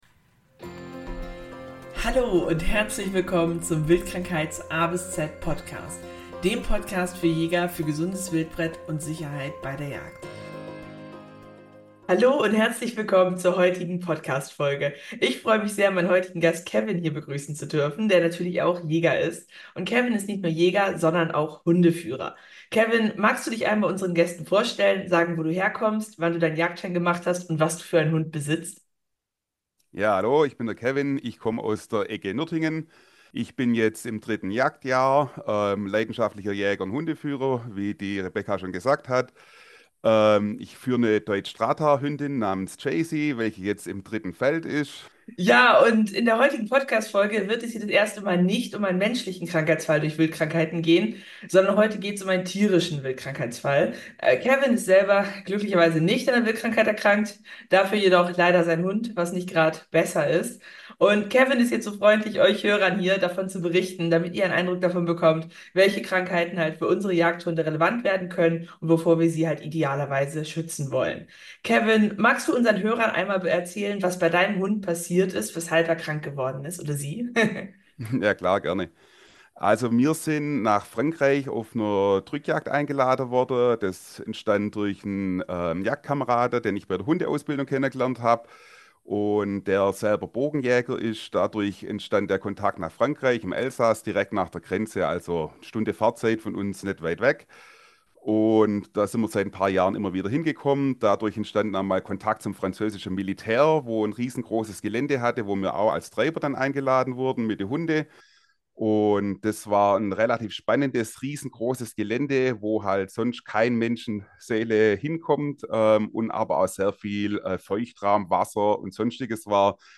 Folge 5: Zeckenstich in Frankreich - schwere Babesiose beim Jagdhund In dieser Folge spreche ich mit einem Jäger, dessen Hund nach einem jagdlichen Einsatz in Frankreich stark von Zecken befallen war – zwei Wochen später erkrankte er schwer.